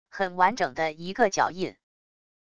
很完整的一个脚印wav音频